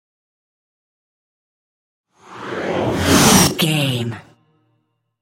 Trailer dramatic raiser short
Sound Effects
Atonal
intense
tension
dramatic
riser
the trailer effect